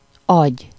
Ääntäminen
Ääntäminen France: IPA: [œ̃ mwa.jø] Tuntematon aksentti: IPA: /mwa.jø/ Haettu sana löytyi näillä lähdekielillä: ranska Käännös Ääninäyte 1. kerék közepe 2. agy Suku: m .